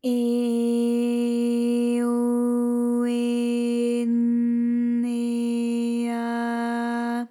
ALYS-DB-001-JPN - First Japanese UTAU vocal library of ALYS.
e_e_o_e_n_e_a.wav